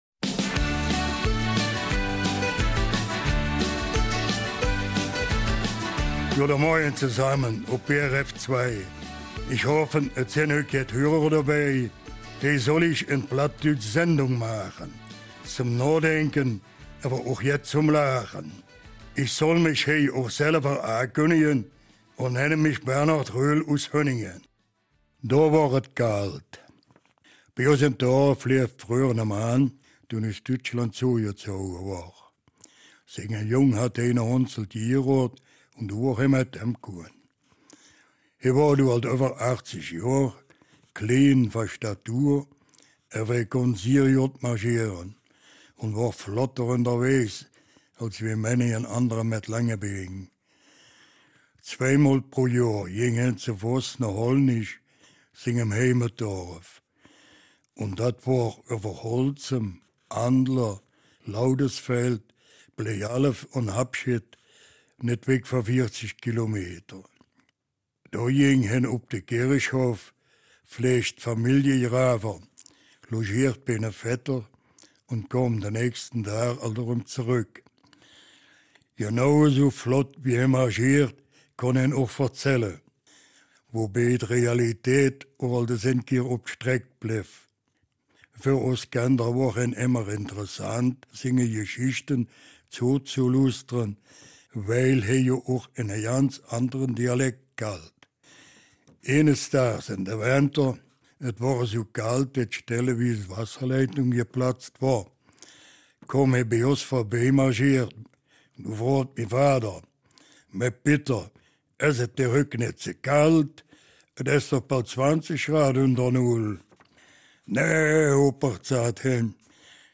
Eifeler Mundart: ''Abgefahrene'' Geschichten